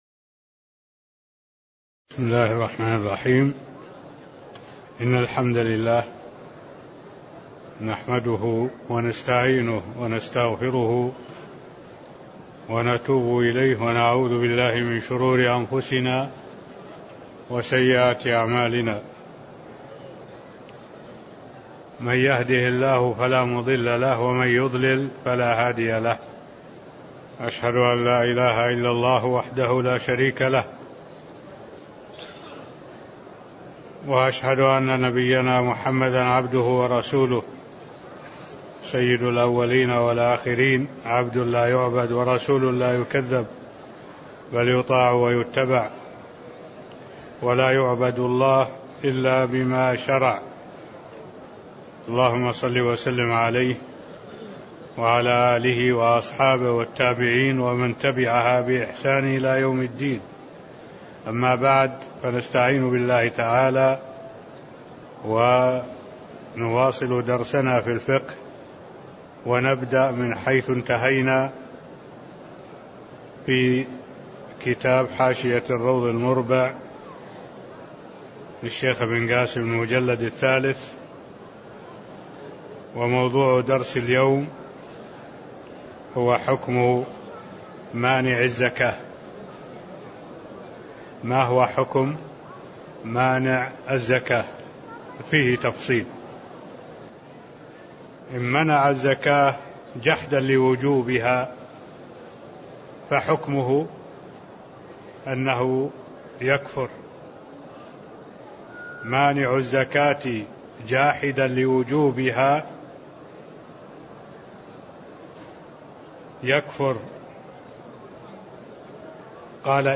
تاريخ النشر ٢١ جمادى الأولى ١٤٢٩ هـ المكان: المسجد النبوي الشيخ: معالي الشيخ الدكتور صالح بن عبد الله العبود معالي الشيخ الدكتور صالح بن عبد الله العبود باب حكم مانع الزكاة (010)